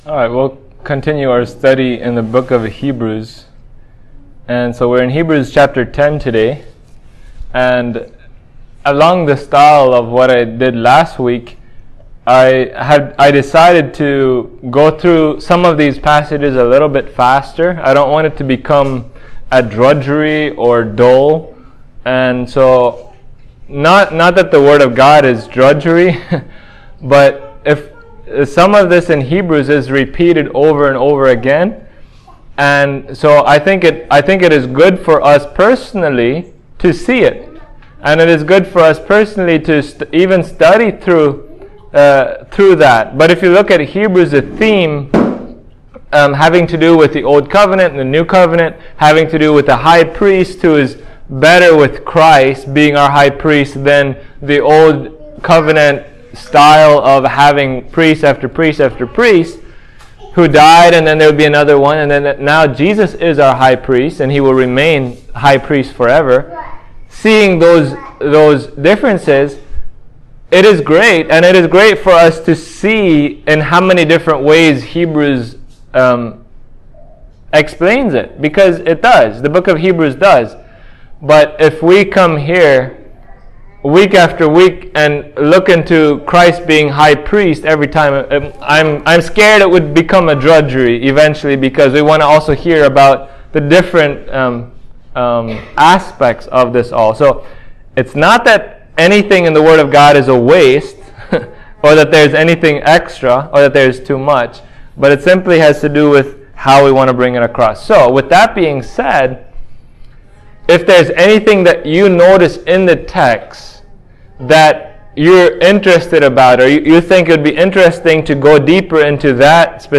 Hebrews 10:1-25 Service Type: Sunday Morning In what ways is Christ’s sacrifice better than the law?